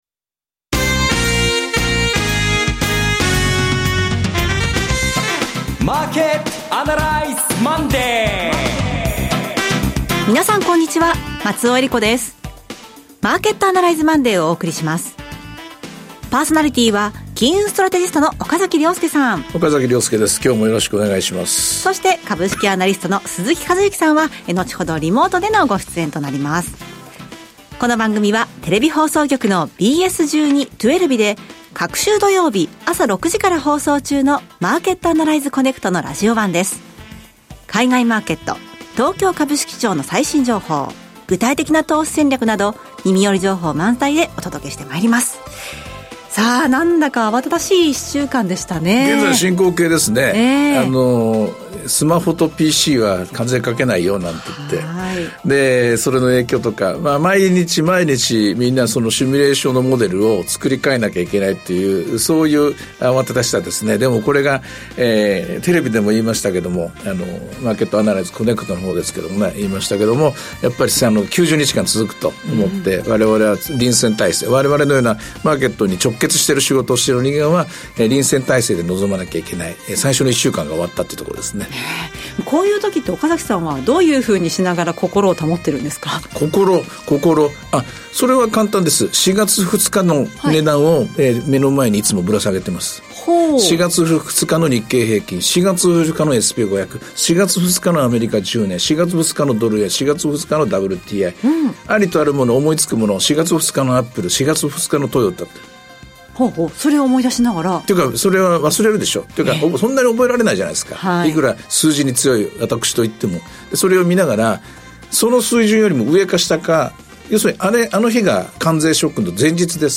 ＢＳ１２ ＴwellＶの「マーケット・アナライズ コネクト」のラジオ版。今週のマーケットはどうなるか？投資家はどう対応すればよいのか、等を２５分間に凝縮してお届けします。